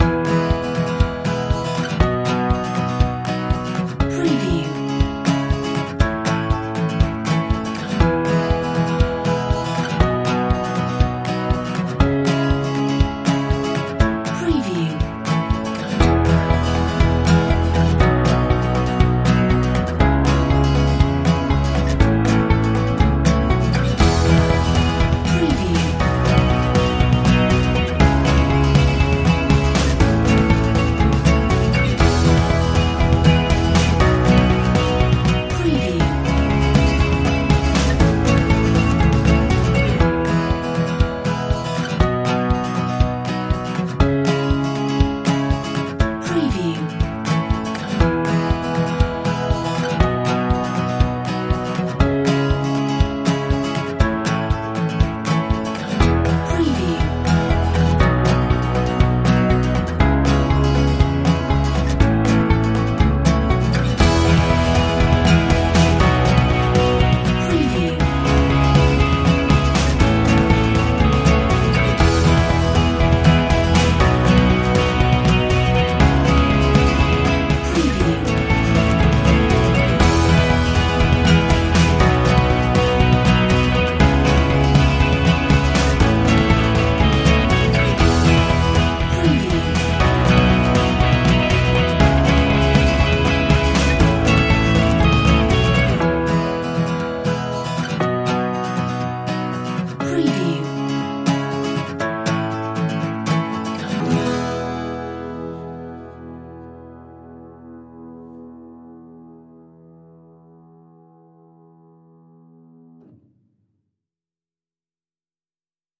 Upbeat folk acoustic